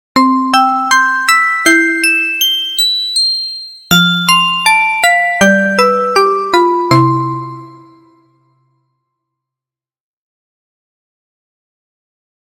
ベルのような高音のキラキラしたイメージです。